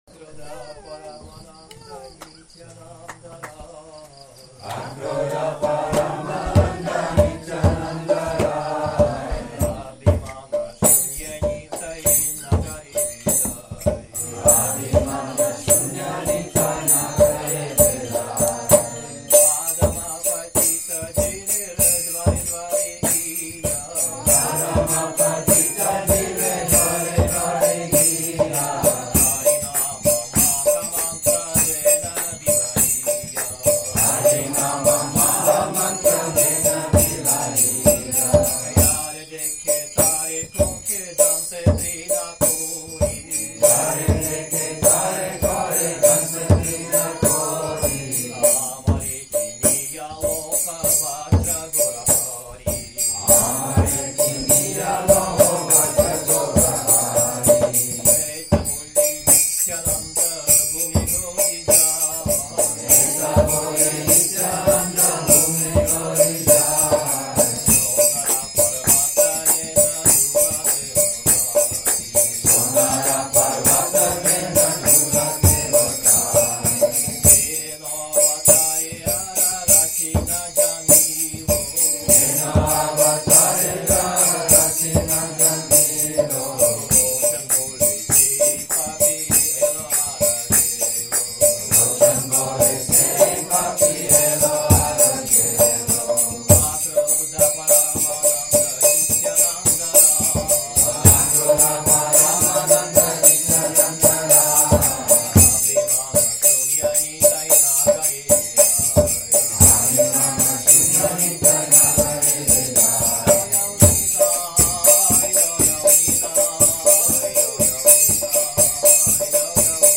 Govinda Farm, Thailand | «Акродха парамананда».